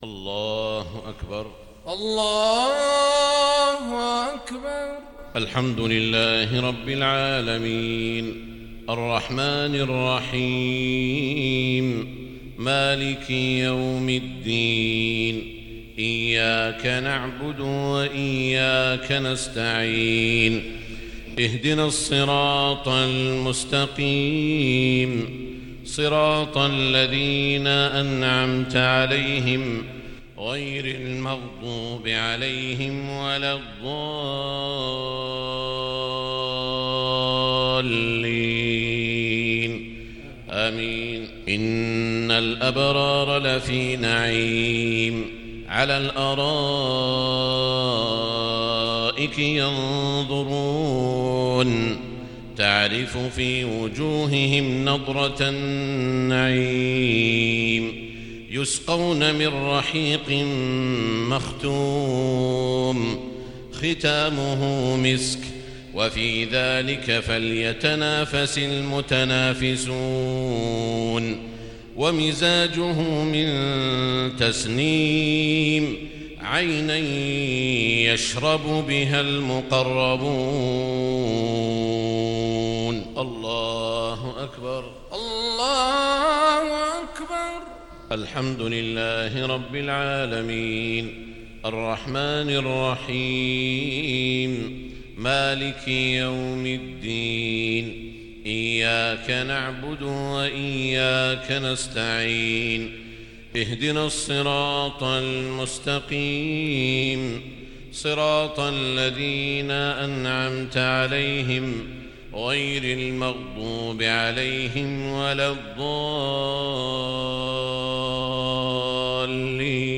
صلاة المغرب للشيخ سعود الشريم 4 شوال 1441 هـ
تِلَاوَات الْحَرَمَيْن .